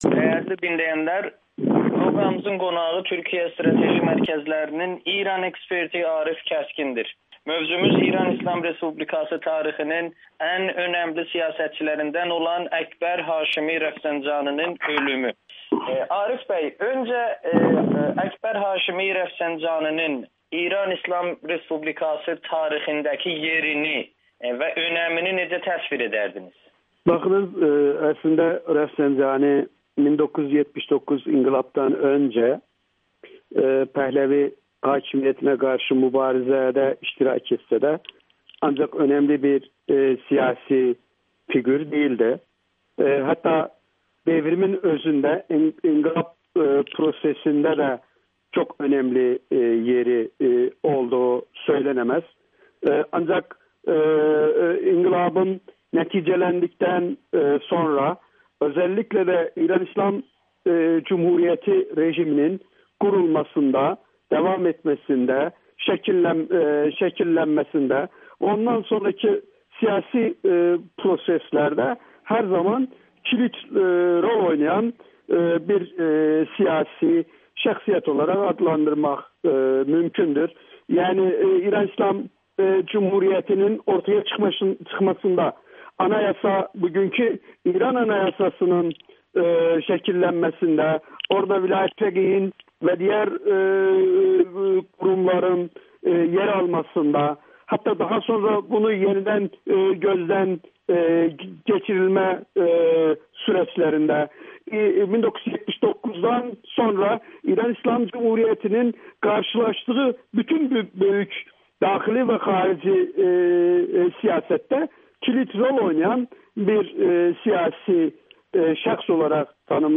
Rəfsəncani olayı İranda mərkəzçi müxalifətin iflasa uğradığını göstərdi [Audio-Müsahibə]